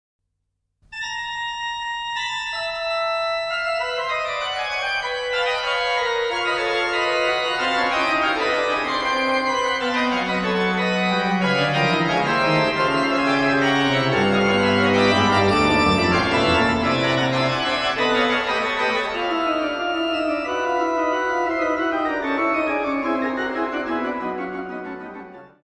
Besetzung Orgel